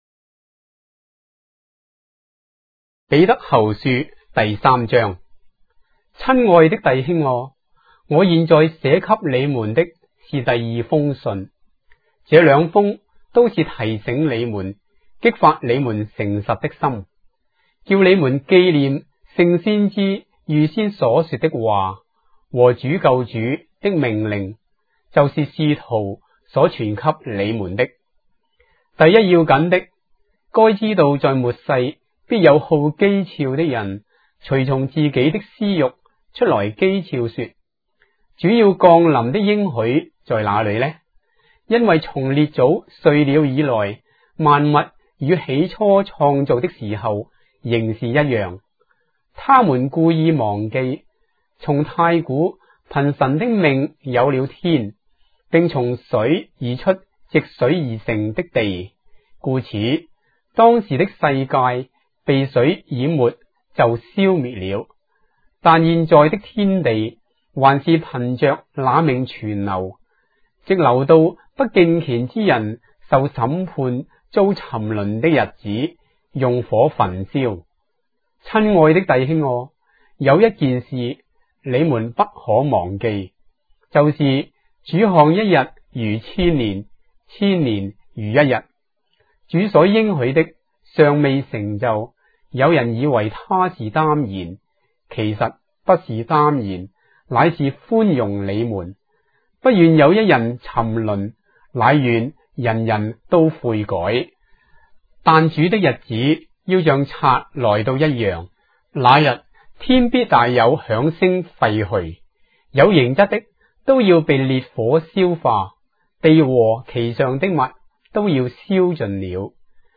章的聖經在中國的語言，音頻旁白- 2 Peter, chapter 3 of the Holy Bible in Traditional Chinese